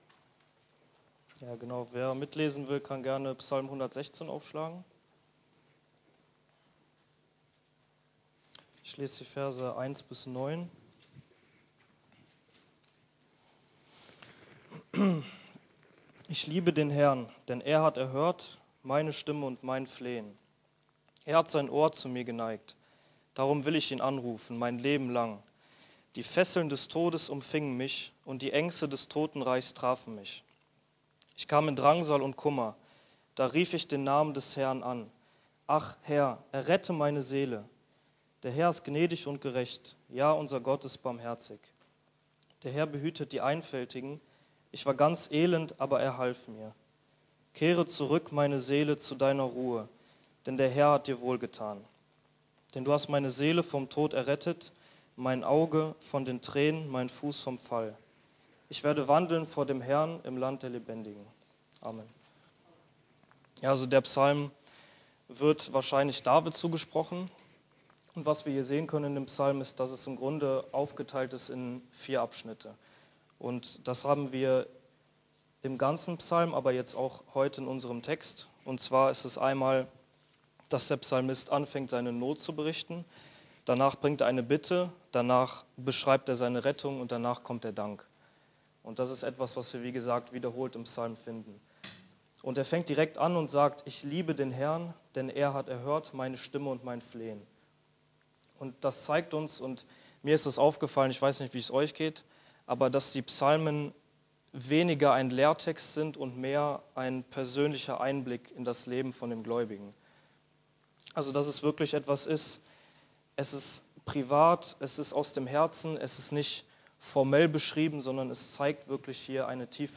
Der HERR hat dir wohlgetan! (Andacht Gebetsstunde)